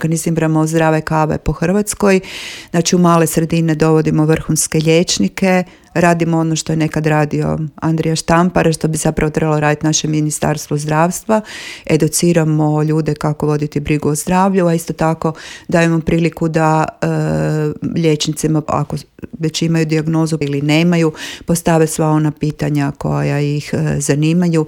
gostovala je u Intervjuu Media servisa u kojem je govorila o svemu što stoji iza ove prestižne nagrade te najpoznatijim projektima udruge